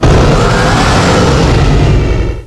cries
The cries from Chespin to Calyrex are now inserted as compressed cries